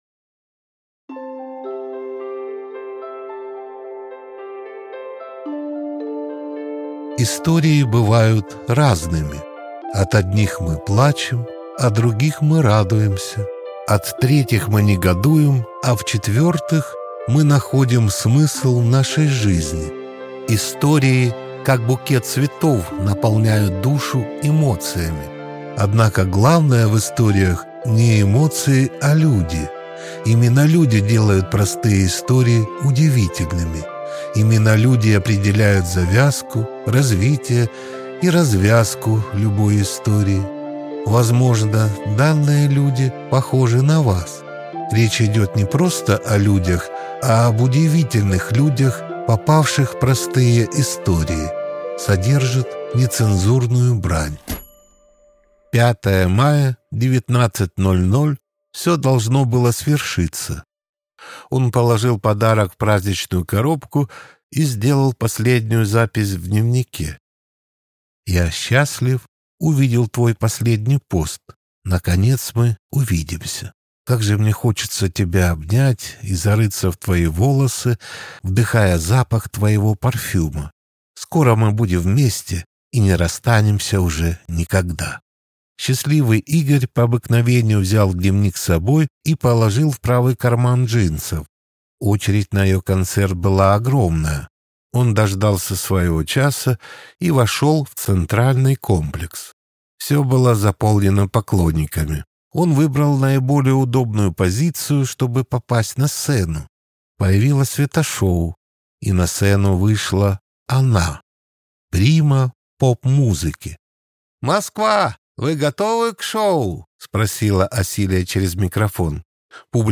Аудиокнига Простые истории об удивительных людях. Сборник | Библиотека аудиокниг